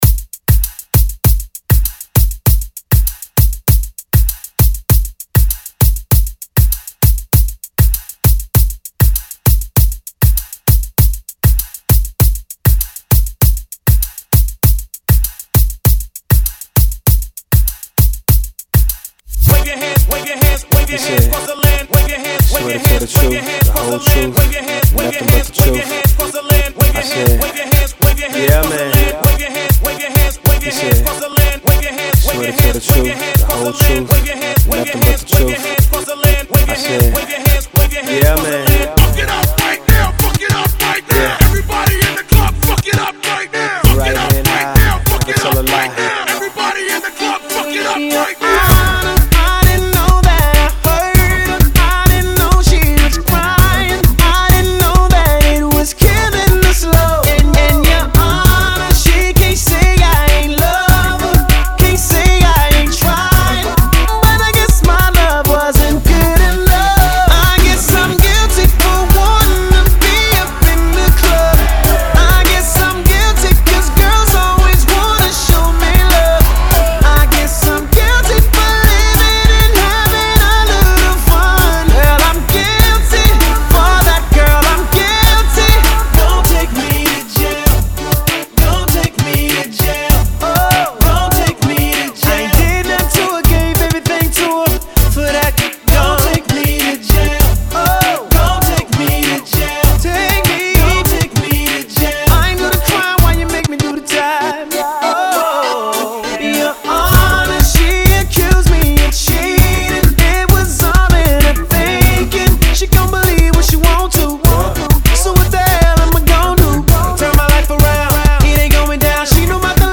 Genre: 70's
Clean BPM: 112 Time